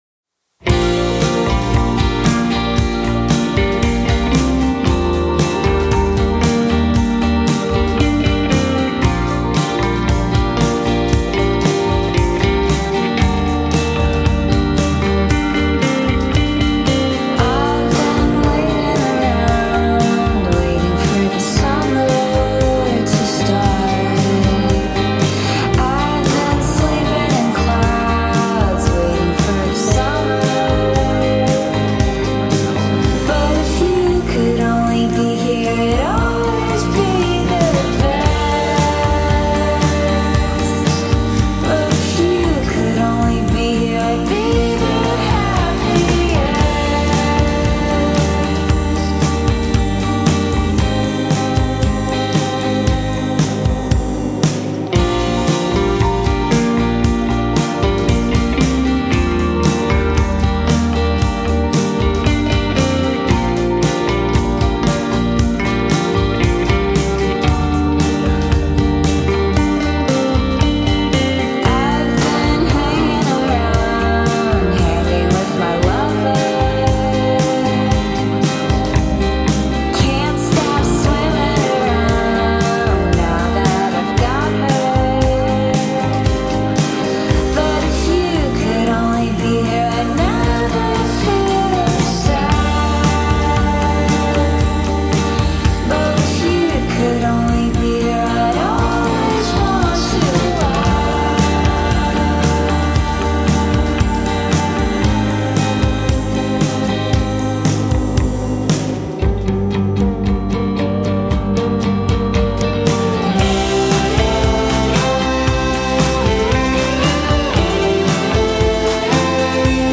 l'etichetta "dream pop" assumeva anche altre sfumature.